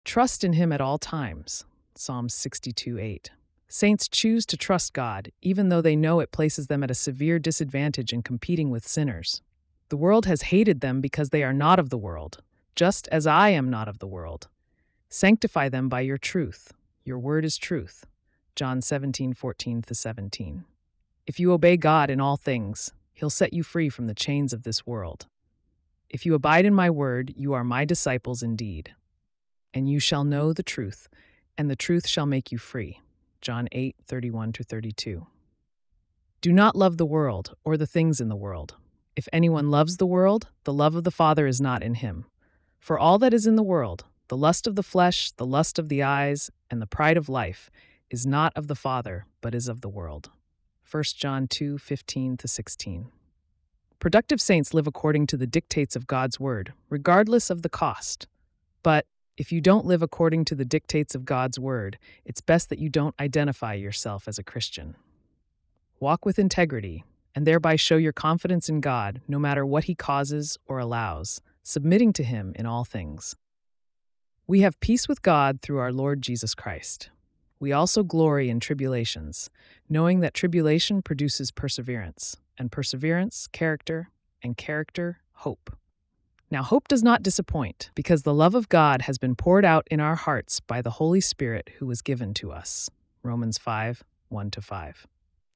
September 1 Evening Devotion